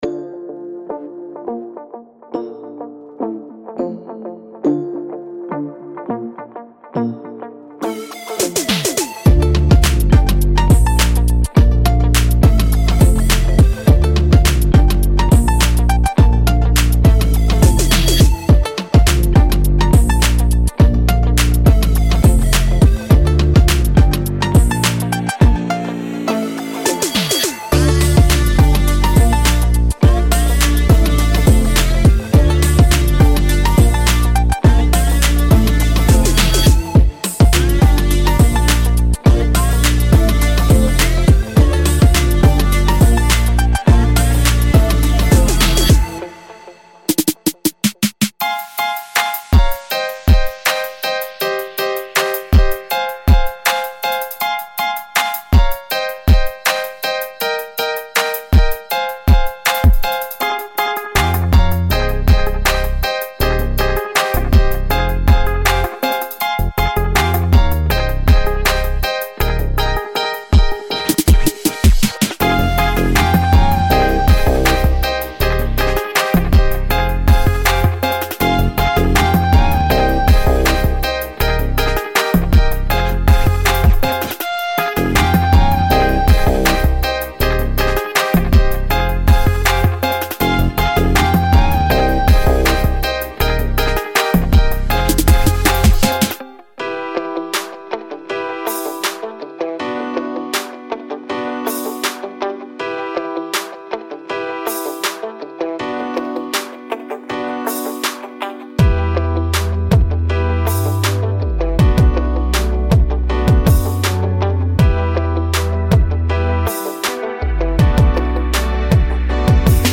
• Real Guitars